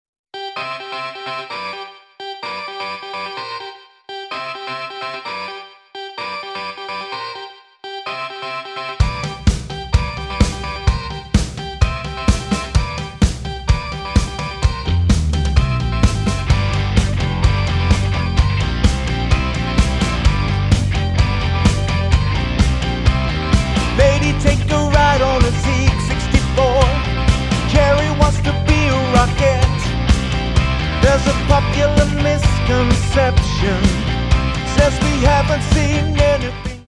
Tonart:C Multifile (kein Sofortdownload.
Die besten Playbacks Instrumentals und Karaoke Versionen .